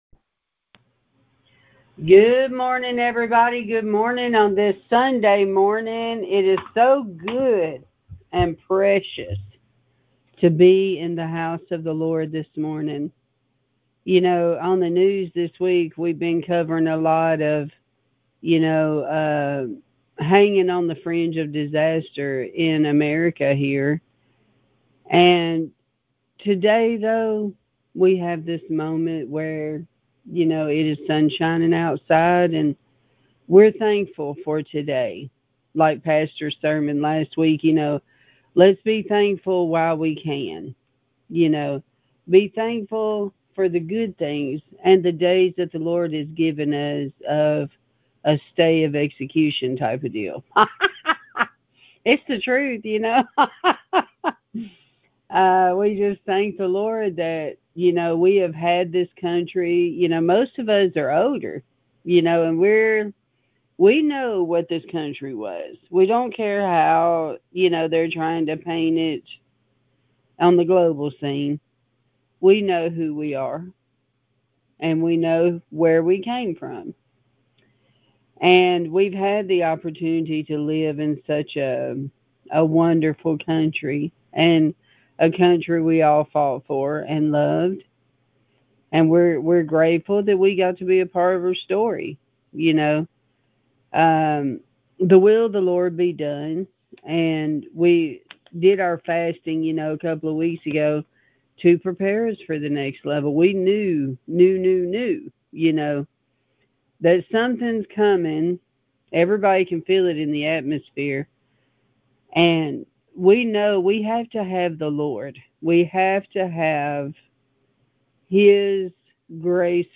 Sermons | Garden of Eden Ministries
We sang two songs: Good, Good, Father & Blessed Assurance.